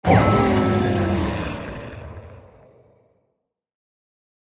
Minecraft Version Minecraft Version 1.21.4 Latest Release | Latest Snapshot 1.21.4 / assets / minecraft / sounds / mob / warden / death_1.ogg Compare With Compare With Latest Release | Latest Snapshot
death_1.ogg